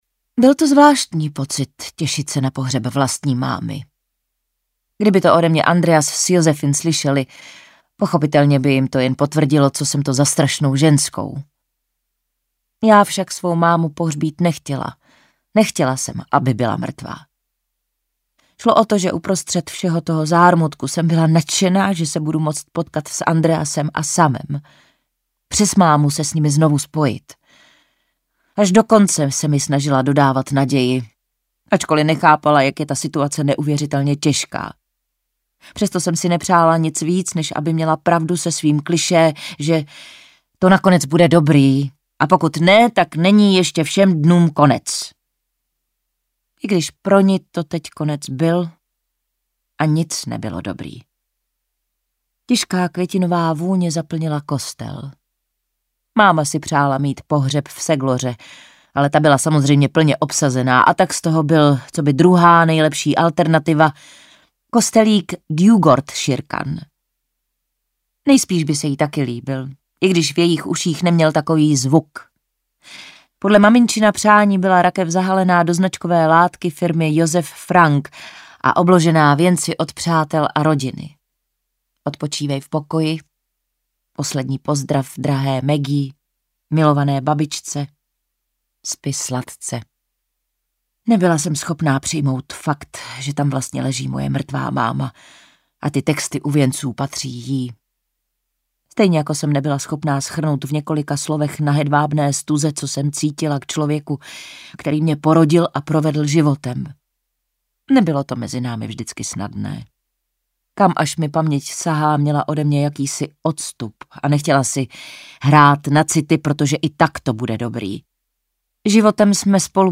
Audiokniha: Tchyně
Čita: Klára Cibulková